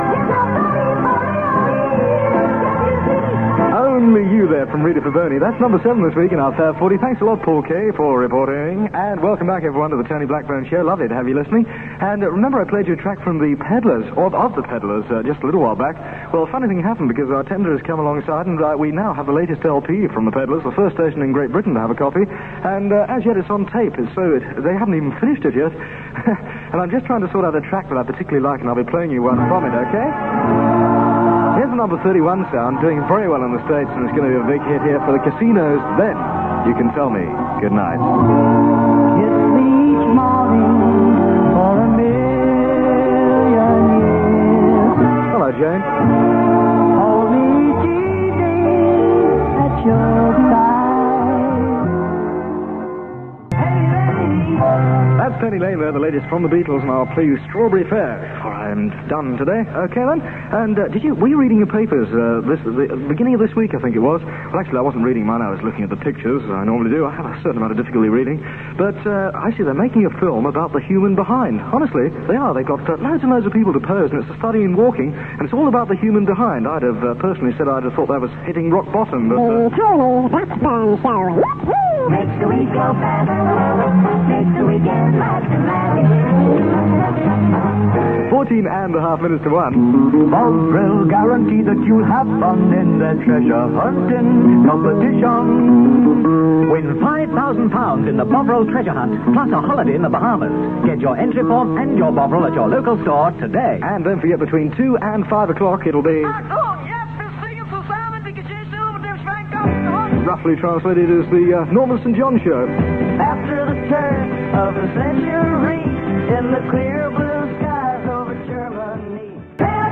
click to hear audio Tony Blackburn on Radio London from 11th February 1967 (duration 3 minutes 32 seconds)